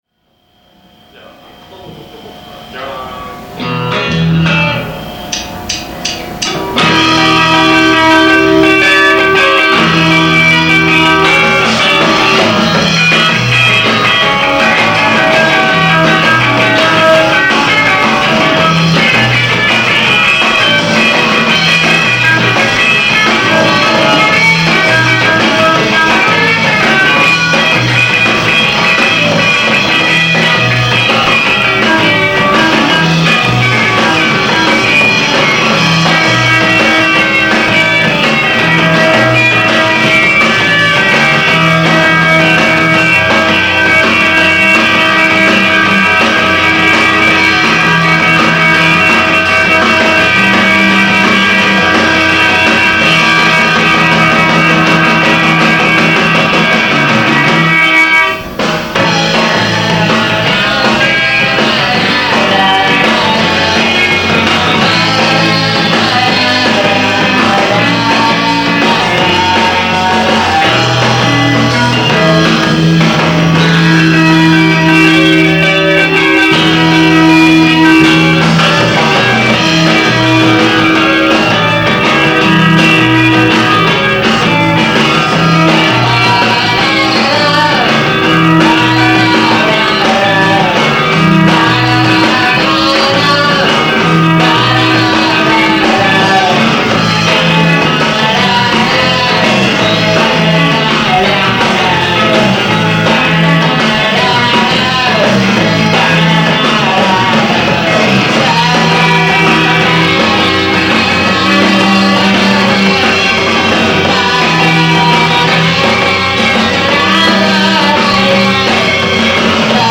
Vox9弦で良いソロを！